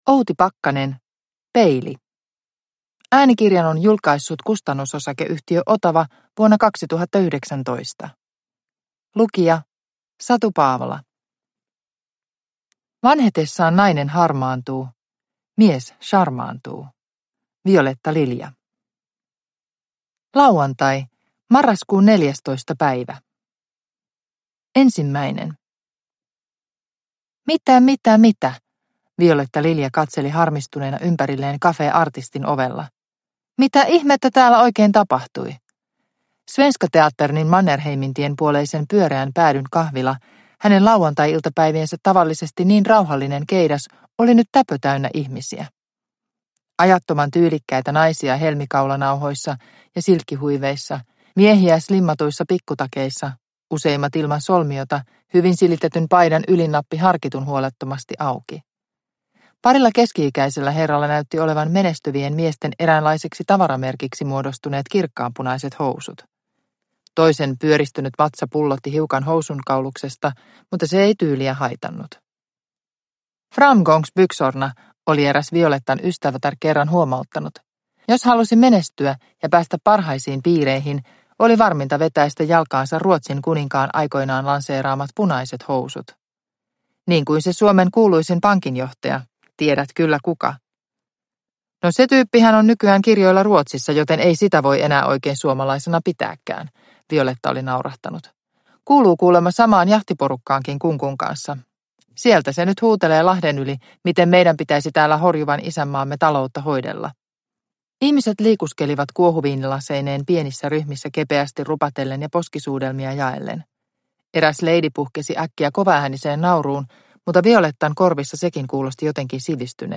Peili – Ljudbok – Laddas ner